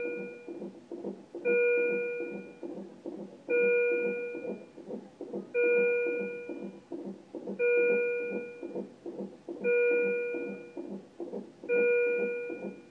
Annoying ping